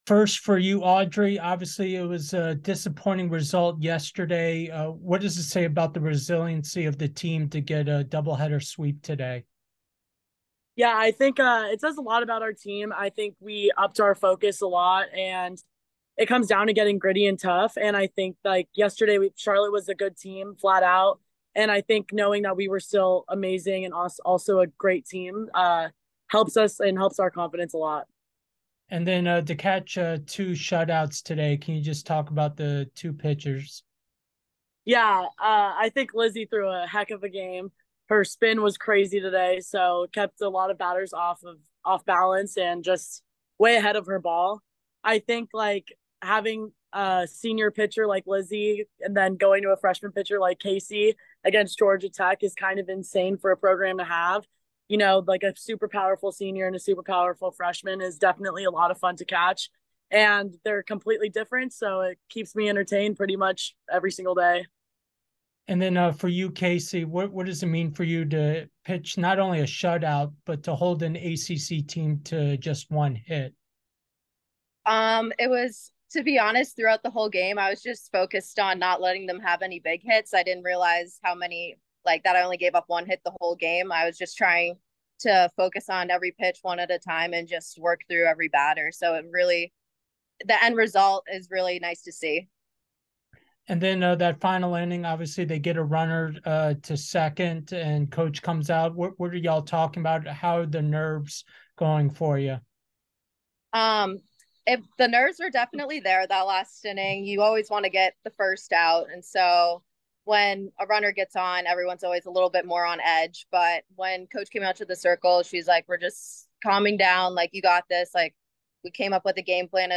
Queen City Classic Day 2 Postgame Interview